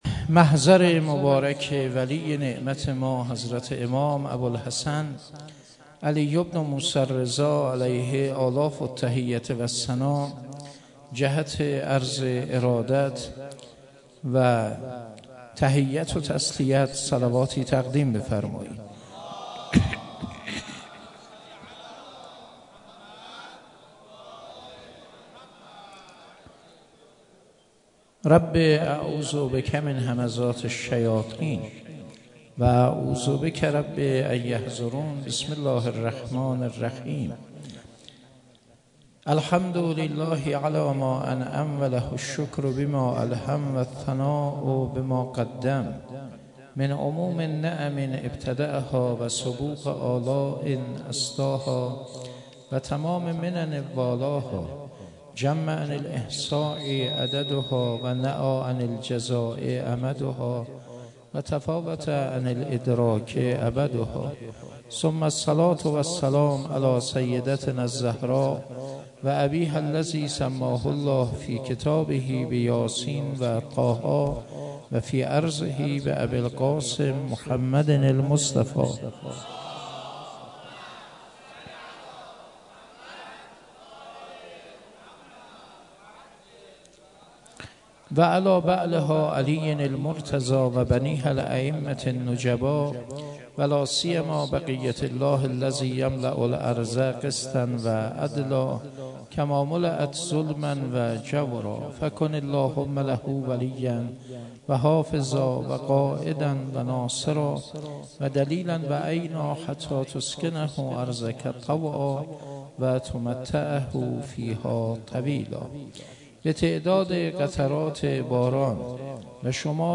21 بهمن 96 - حسینیه کربلایی ها - سخنرانی